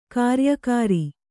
♪ kāryakāri